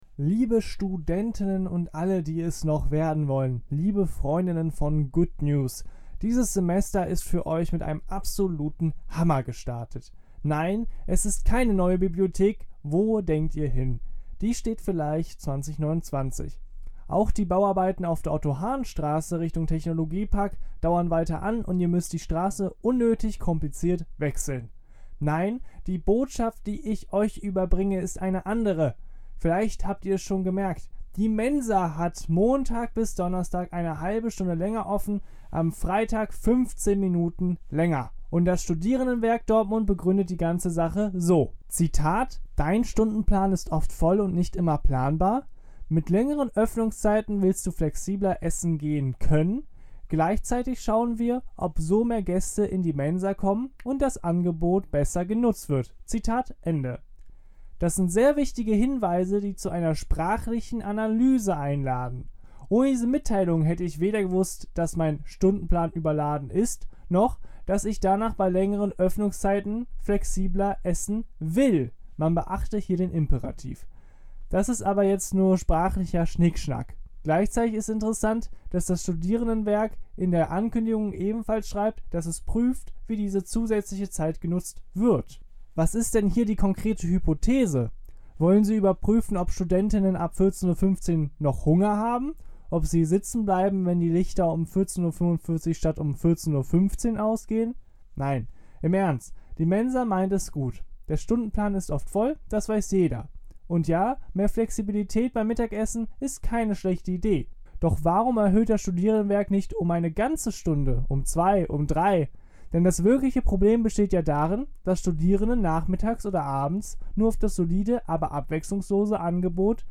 Serie: Glosse